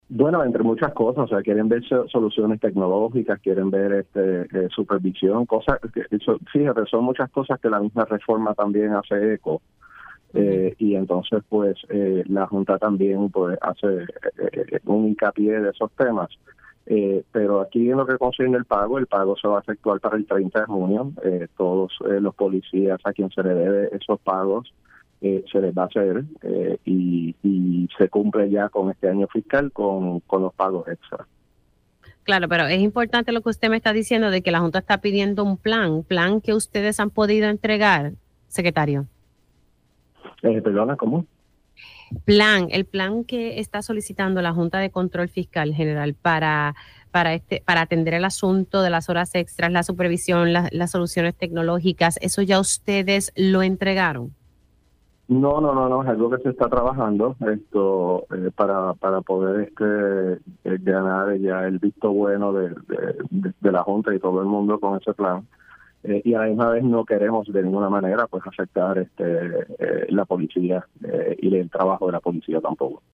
El secretario de Seguridad Pública, Arthur Garffer indicó en Pega’os en la Mañana que solamente falta la firma del director ejecutivo de la Junta de Supervisión Fiscal (JSF), Robert Mujica para poder aprobar los pagos de horas extra a los oficiales del Negociado de la Policía de Puerto Rico (NPPR).